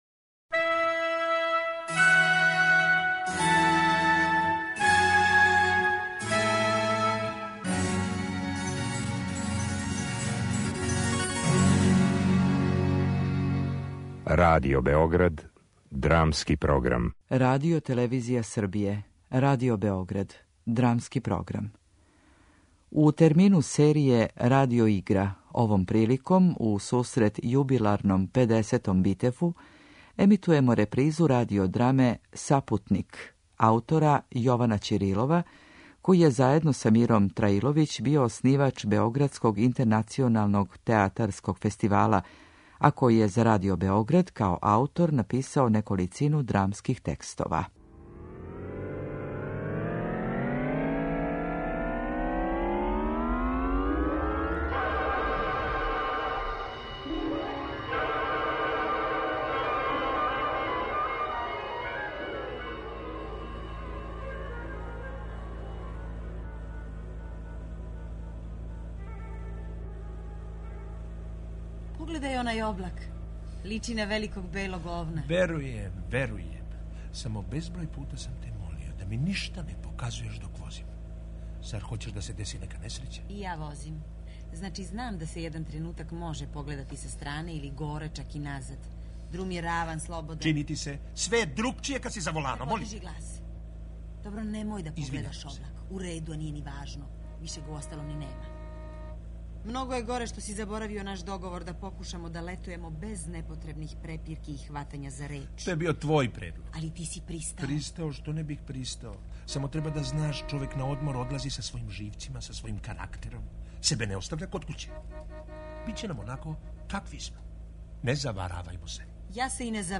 Радио игра: Сапутник
Емитујемо репризу радио драме аутора Јована Ћирилова, драматурга и писца, који је заједно са Миром Траиловић био оснивач Београдског интернационалног театарског фестивала.
RADIO IGRA.mp3